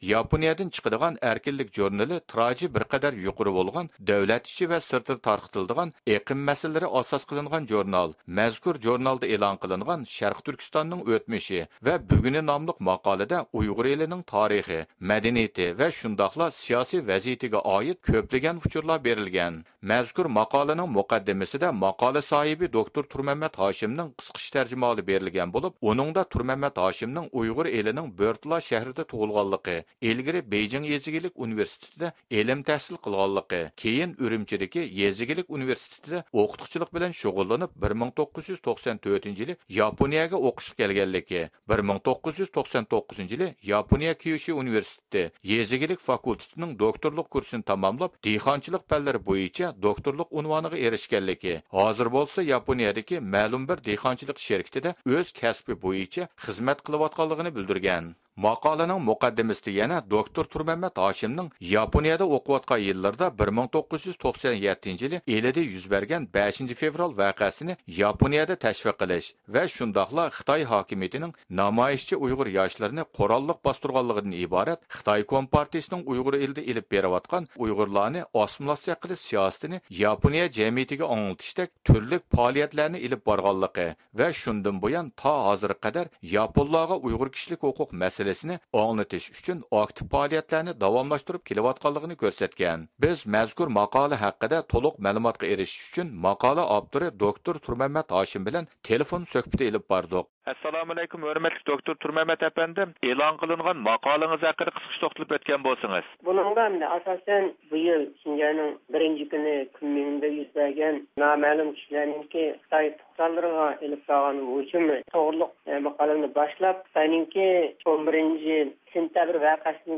تېلېفون سۆھبىتى ئېلىپ باردۇق.